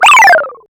SCI ARCADE.wav